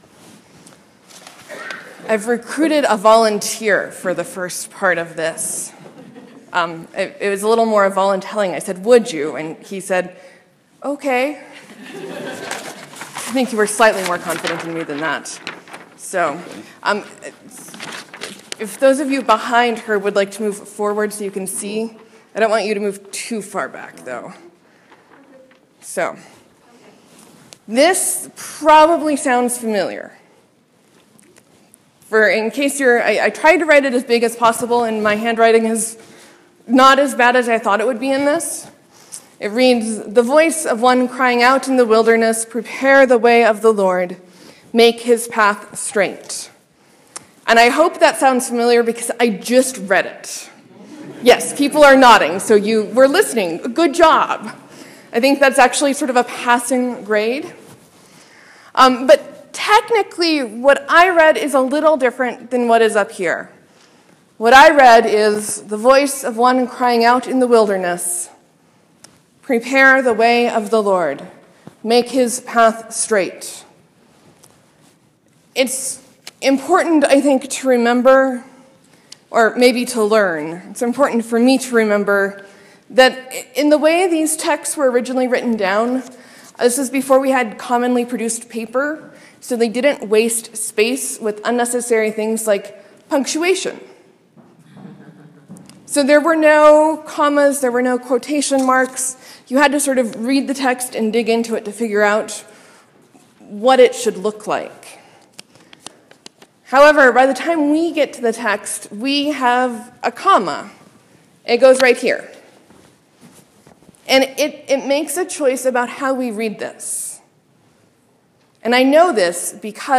Morsels & Stories: I talk about Matthew 3:3.